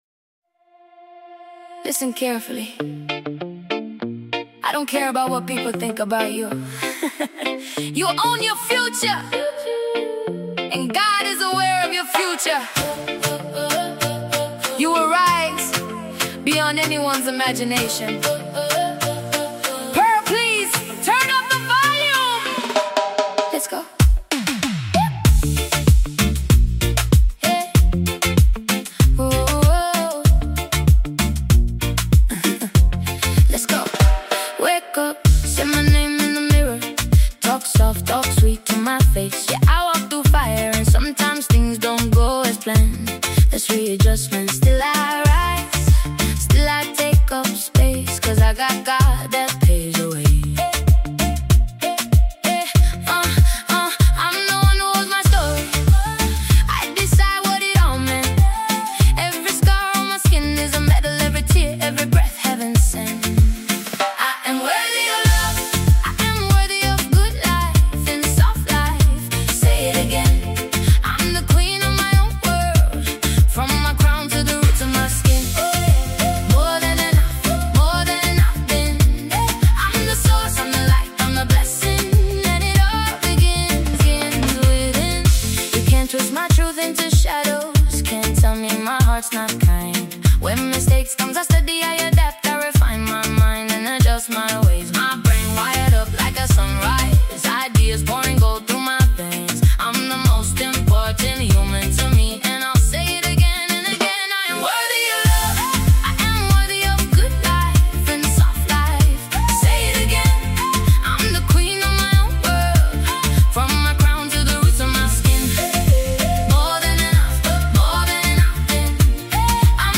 Home » gospel
As it plays, the tone feels more grounded.